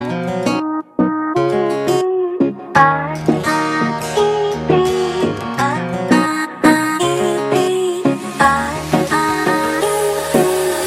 Kategorien: Elektronische